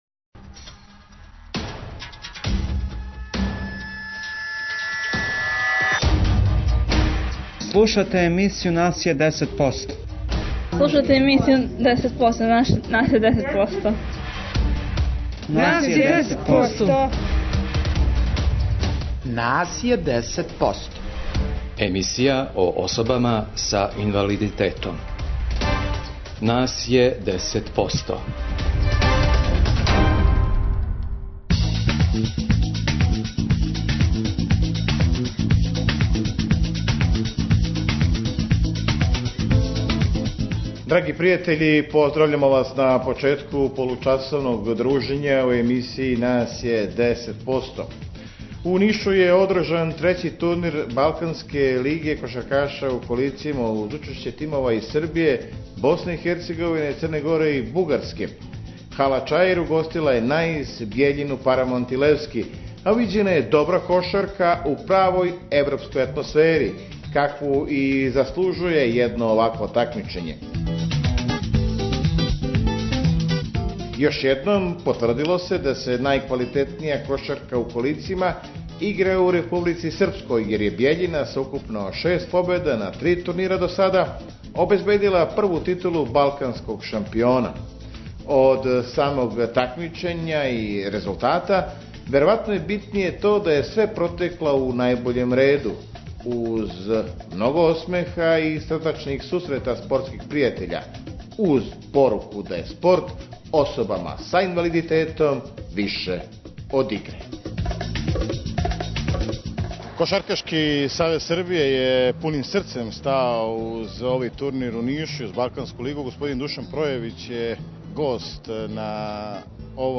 У новој емисији која је посвећена особама са инвалидитетом, припремили смо репортажу са турнира Балканске лиге кошаркаша у колицима, који је одржан у Нишу. Домаћин такмичења био је КК Наис, уз учешће екипа из Бугарске, Црне Горе и Републике Српске.